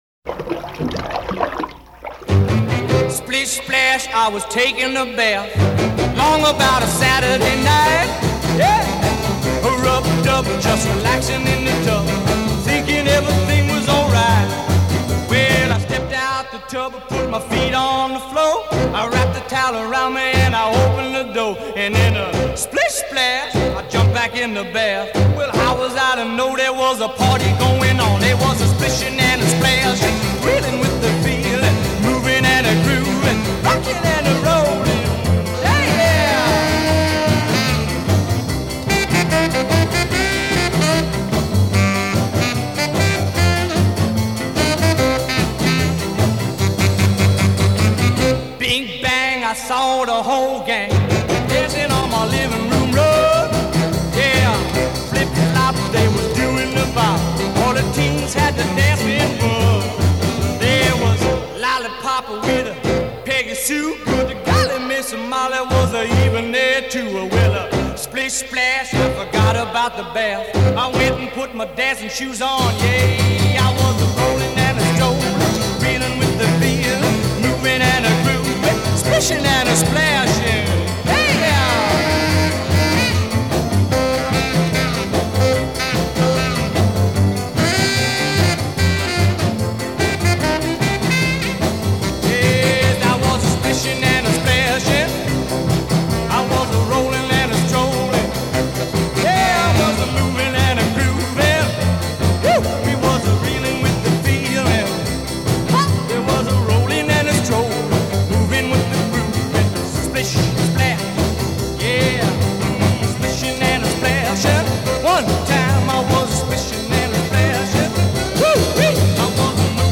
Rock & Roll